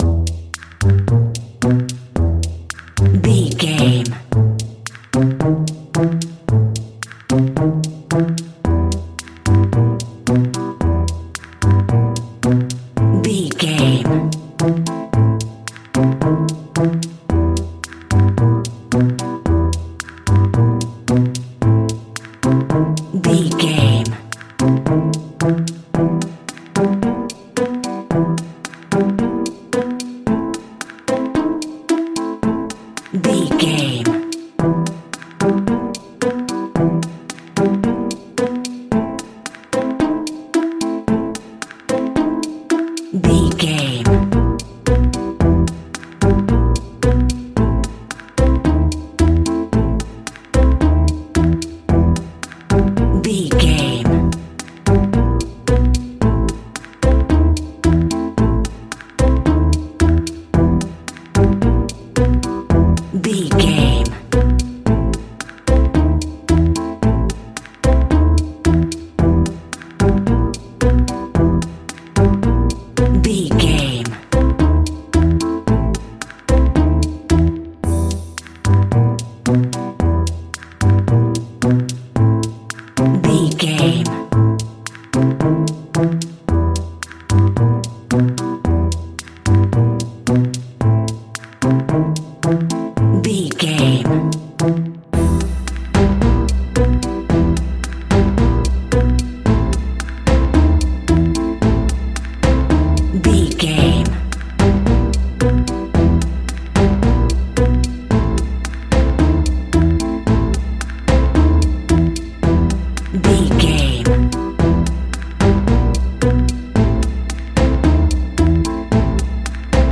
Aeolian/Minor
ominous
dark
eerie
piano
drums
synthesizer
spooky
horror music